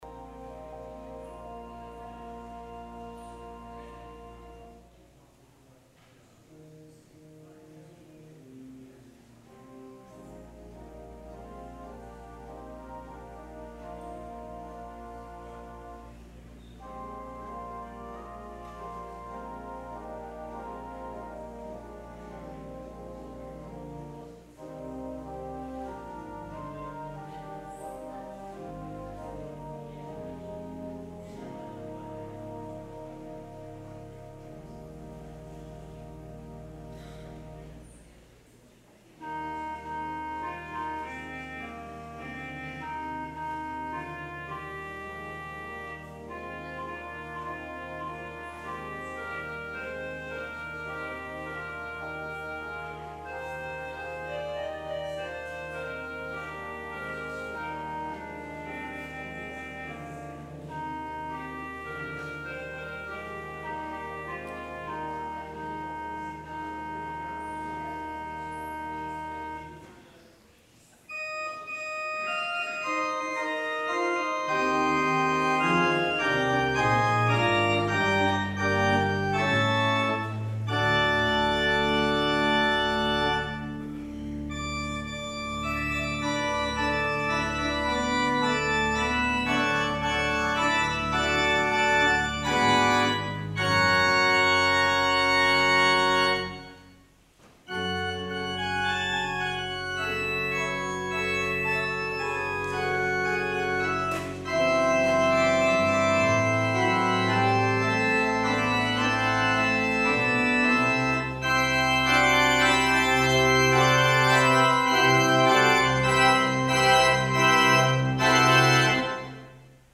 April 7, 2019 Service
Traditional Sermon